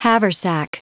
Prononciation du mot haversack en anglais (fichier audio)
Prononciation du mot : haversack